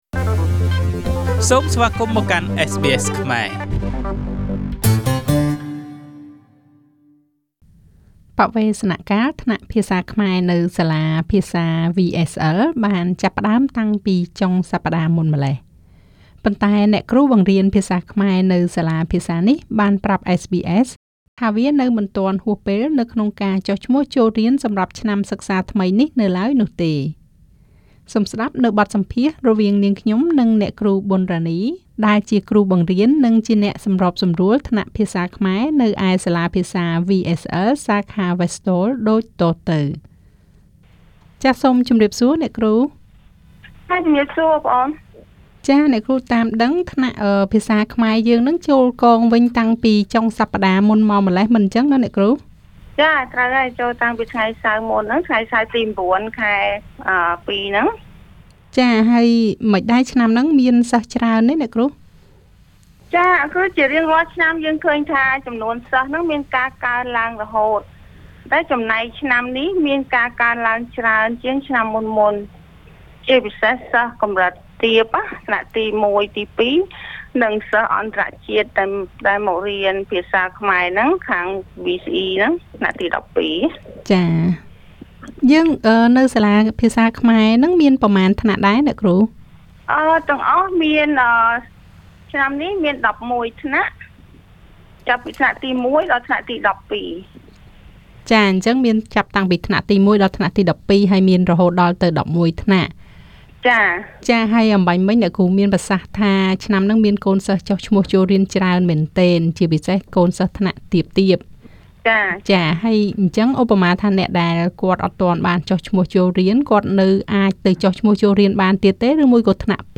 She explains how to enroll in the interview.